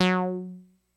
Arturia Microbrute Dynamic Saw " Arturia Microbrute Dynamic Saw F3（54 F2TEDM
标签： MIDI网速度80 F3 MIDI音符-54 Arturia-Microbrute 合成器 单票据 多重采样
声道立体声